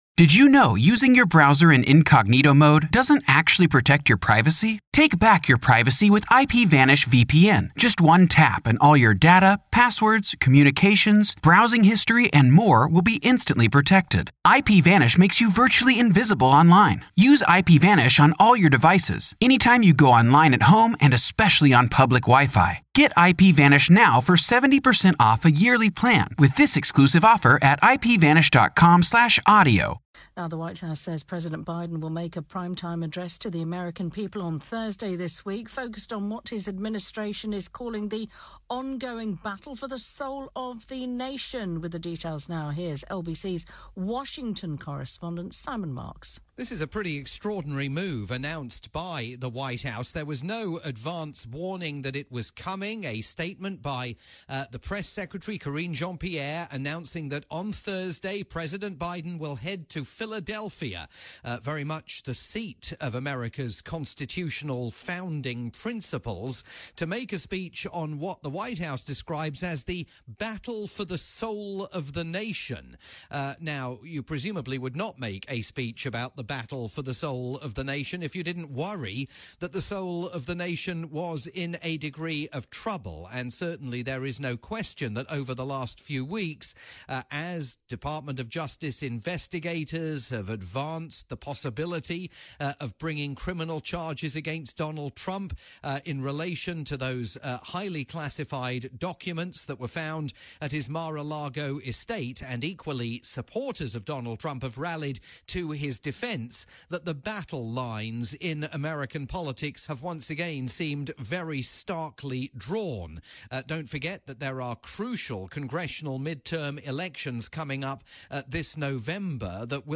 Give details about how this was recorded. update for LBC News' breakfast sequence